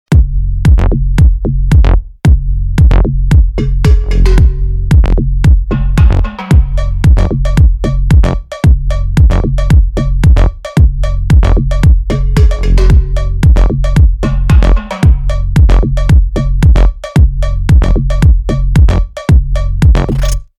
• Качество: 320, Stereo
ритмичные
Electronic
без слов
басы
цикличные